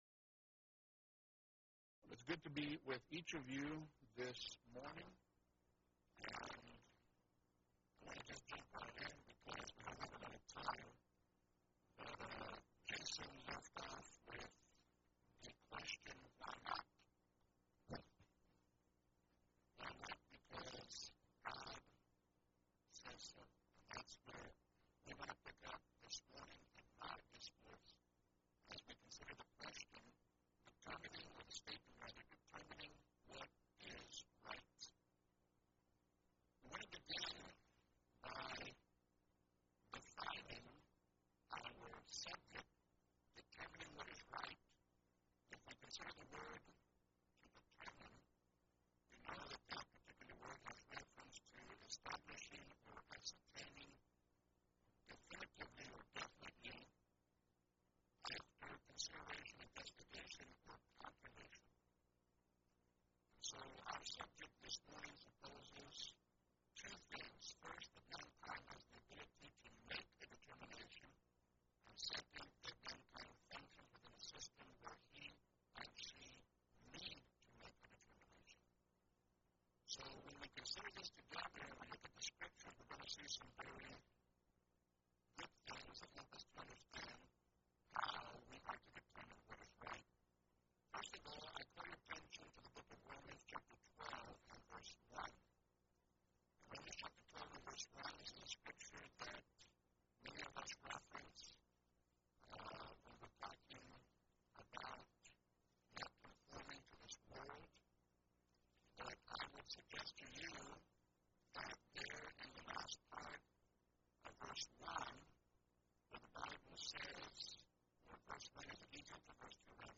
Event: 2nd Annual Young Men's Development Conference